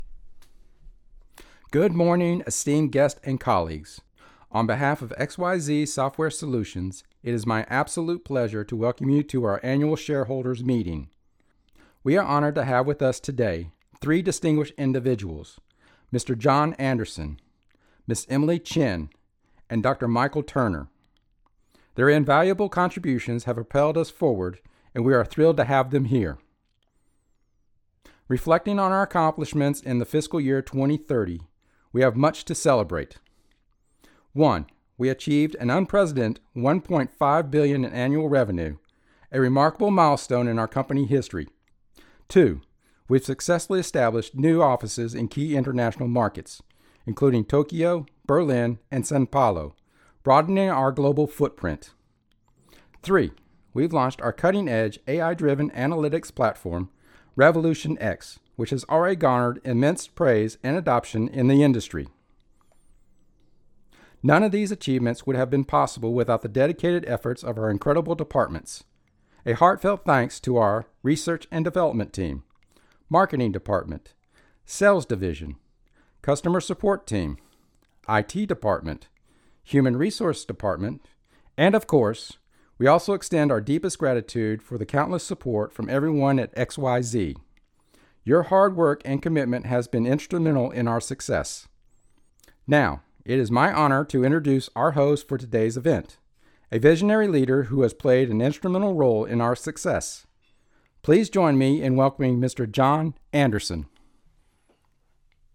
English Speaking (US), neutral and southern dialects, young adult to mature senior voice
Sprechprobe: Industrie (Muttersprache):
Rode NT1 condenser mic Rode Pop screen Zoom H1 XLR Recorder Focusrite Scarlett 2i12 (3rd gen) interface Audacity DAW